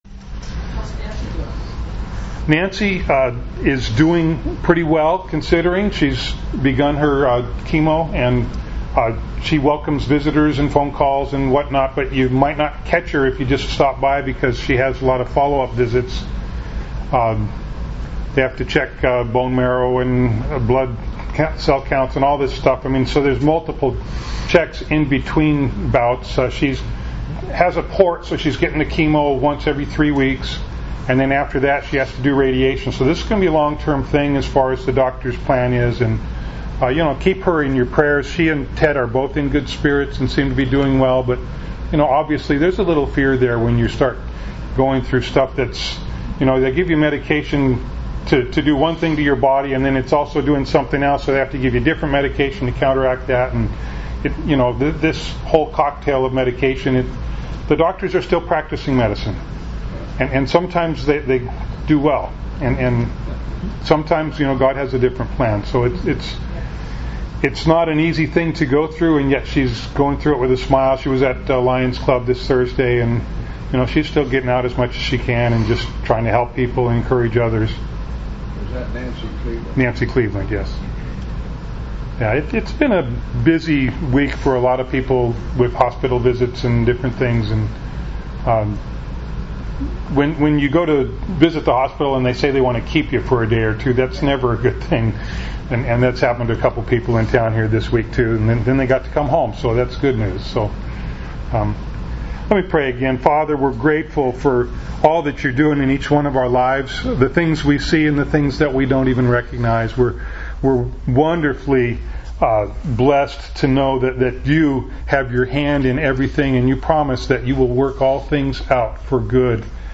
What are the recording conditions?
Acts 7:1-8:1a Service Type: Sunday Morning Bible Text